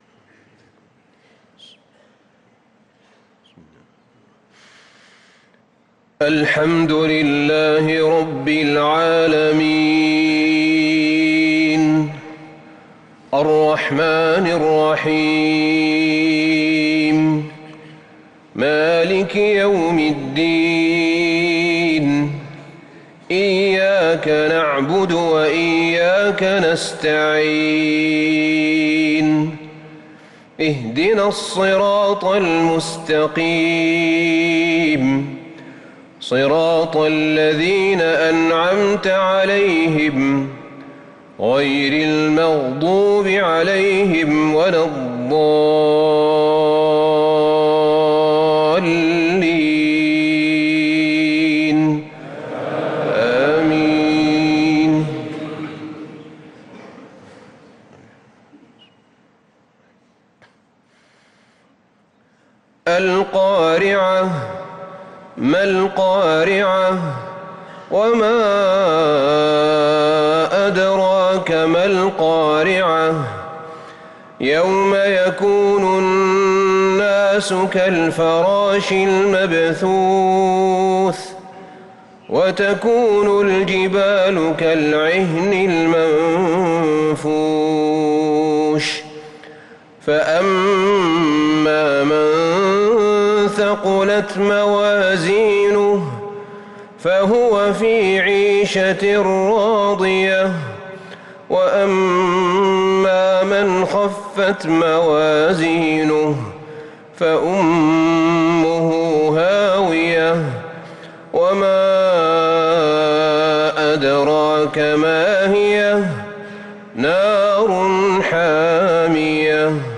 صلاة المغرب للقارئ أحمد بن طالب حميد 16 جمادي الأول 1445 هـ
تِلَاوَات الْحَرَمَيْن .